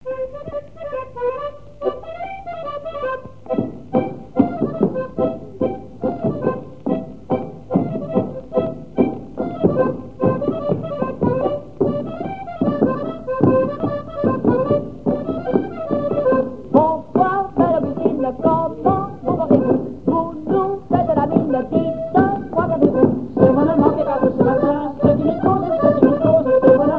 Chants brefs - A danser
polka piquée
airs de danses issus de groupes folkloriques locaux
Pièce musicale inédite